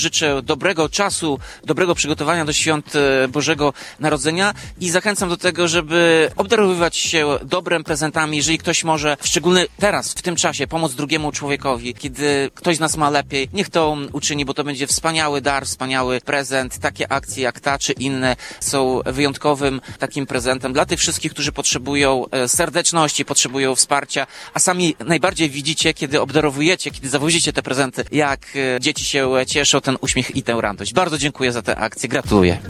W Mikołajkowym Autobusie Radia 5 nie brakuje również życzeń. Tomasz Andrukiewicz prezydent Ełku życzył przede wszystkim zdrowia.